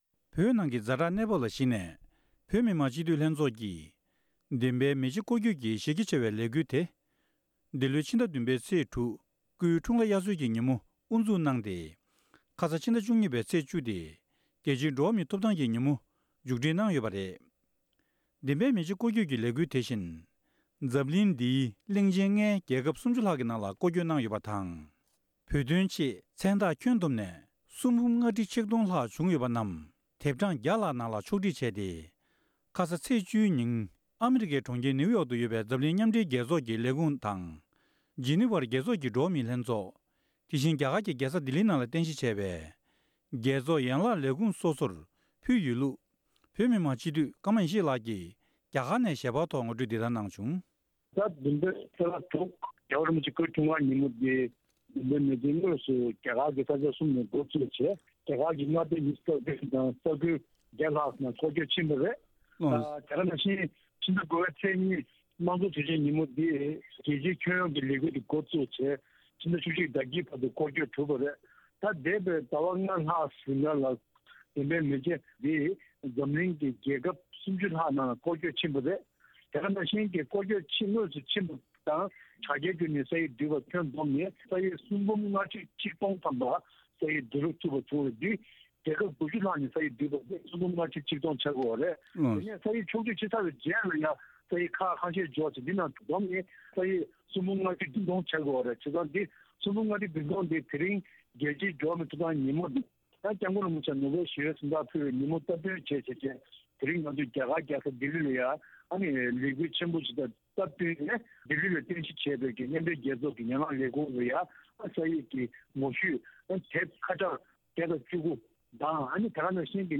སྒྲ་ལྡན་གསར་འགྱུར།
གནས་འདྲི་ཞུས་ཏེ་ཕྱོགས་སྒྲིག་ཞུས་པ་ཞིག